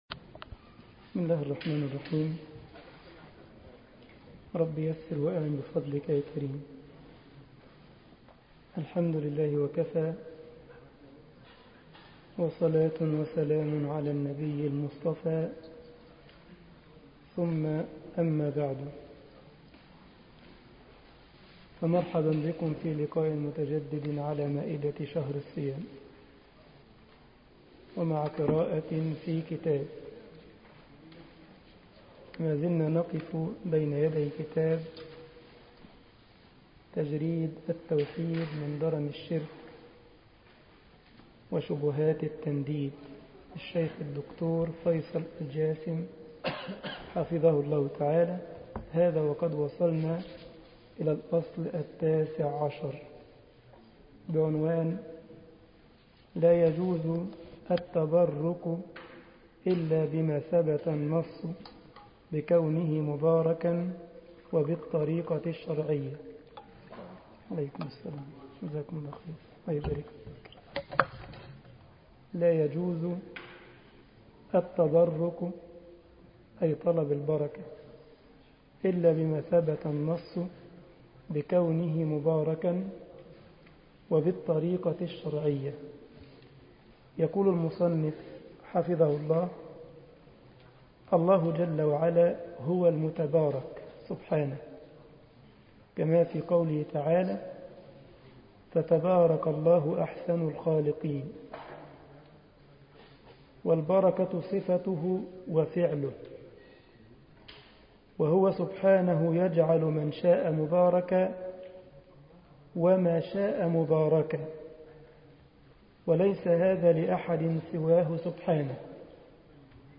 مسجد الجمعية الإسلامية بالسارلند ـ ألمانيا درس 26 رمضان 1433 هـ